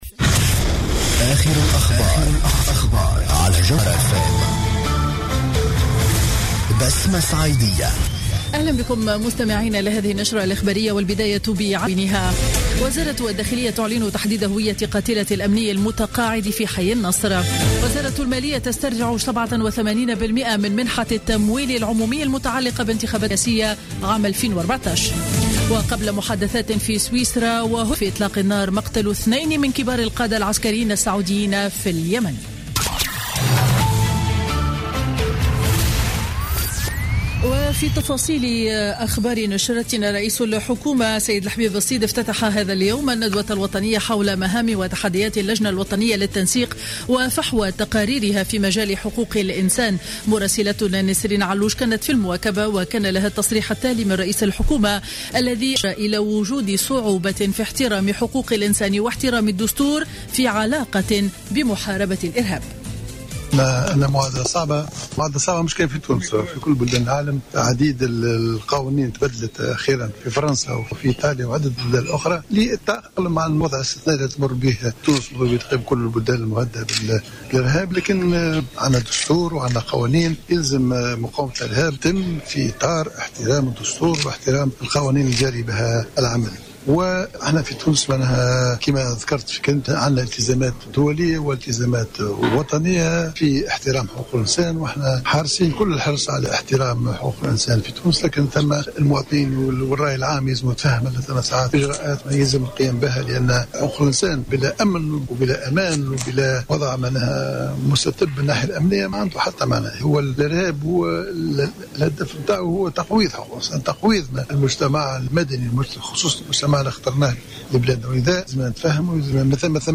نشرة أخبار منتصف النهار ليوم الاثنين 14 ديسمبر 2015